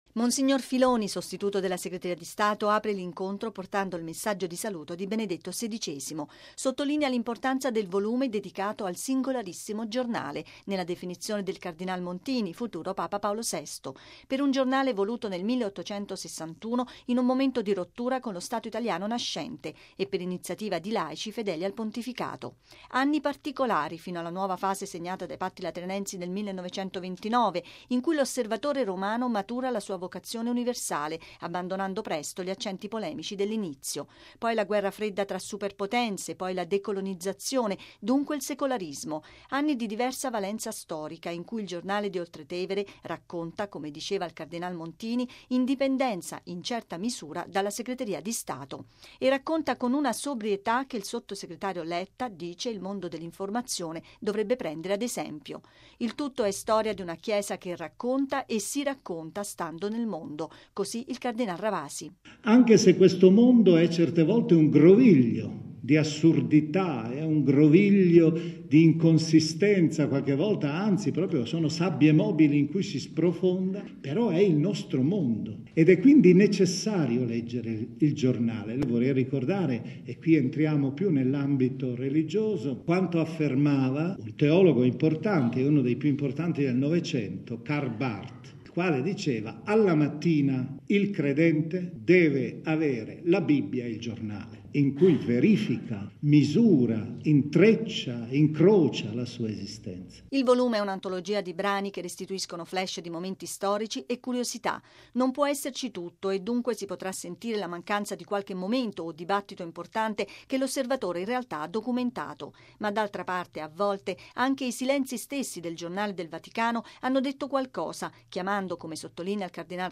◊   Un messaggio di saluto del Papa è giunto alla presentazione del volume “Singolarissimo giornale. I 150 anni dell’‘Osservatore Romano’”, che si è svolta ieri pomeriggio all’Ambasciata d’Italia presso la Santa Sede, alla presenza del presidente della Repubblica, Giorgio Napolitano.